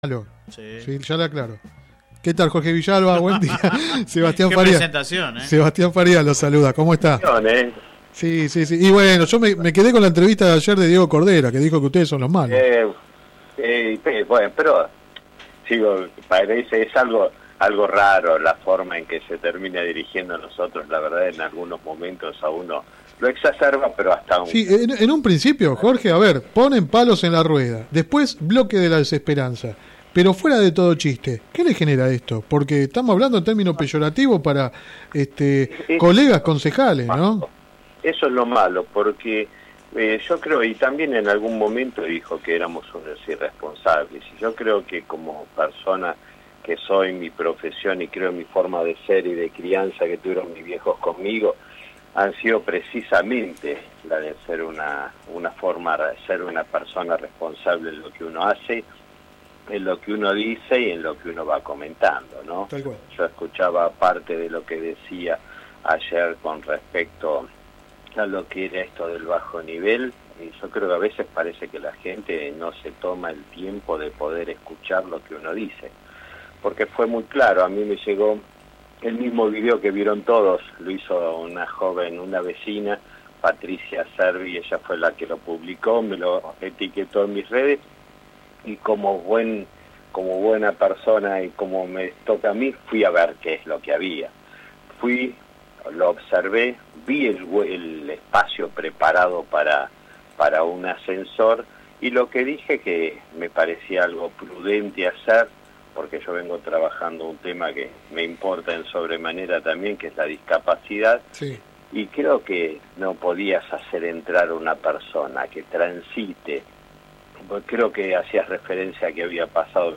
El concejal opositor habló en el programa radial Sin Retorno (lunes a viernes de 10 a 13 por GPS El Camino FM 90 .7 y AM 1260).
Click acá entrevista radial